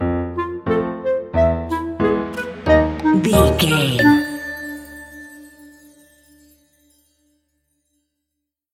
Uplifting
Aeolian/Minor
Slow
flute
oboe
piano
percussion
silly
circus
goofy
cheerful
Light hearted
quirky